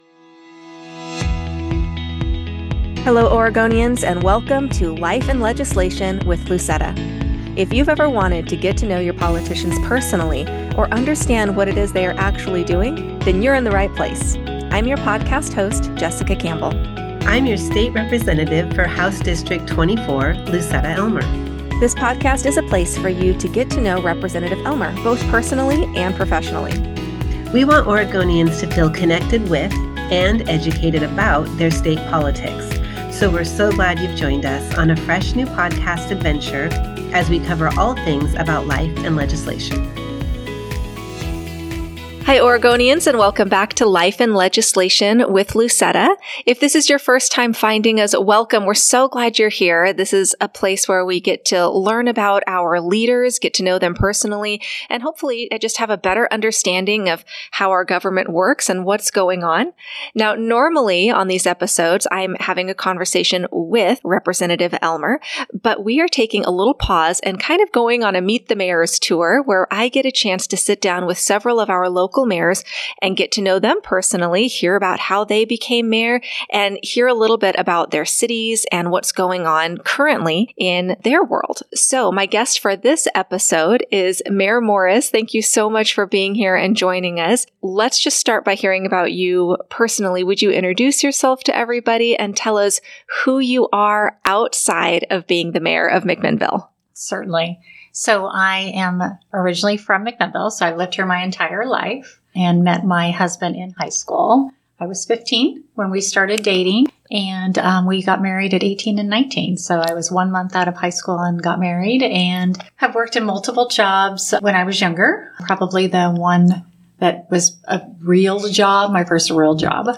In this episode, we continue with our "Meet the Mayors" series and welcome Mayor Morris to the podcast.